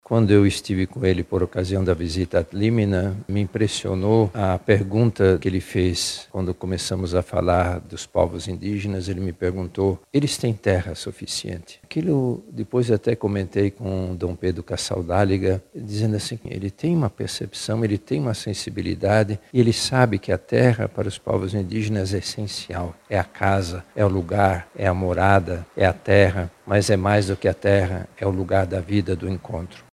Cardeal Leonardo Steiner se pronuncia sobre o falecimento do Papa Bento XVI